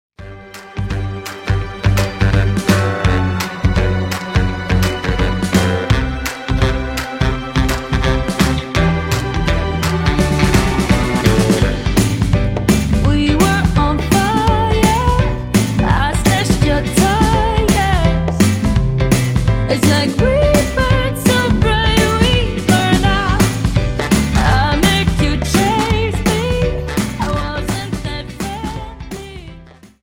Dance: Jive 42